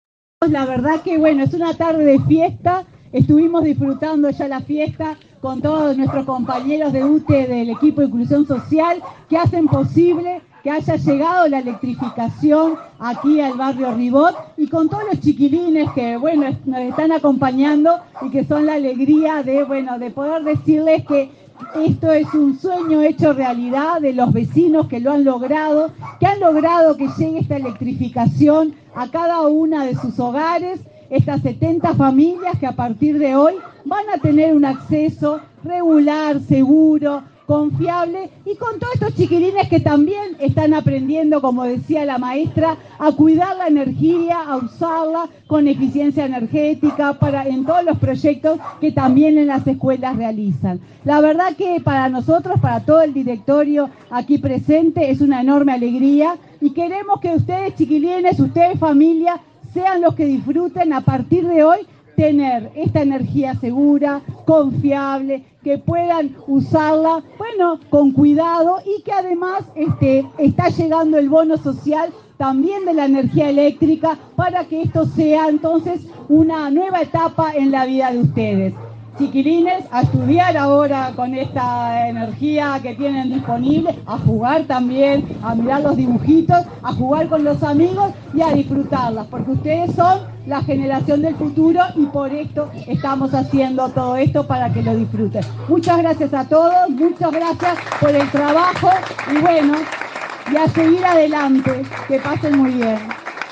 Palabras de la presidenta de UTE, Silvia Emaldi, en inauguración de obras en el barrio Ribot
En esta oportunidad, 50 familias del barrio Ribot fueron beneficiarias. Participó del acto, la presidenta de la empresa estatal, Silvia Emaldi.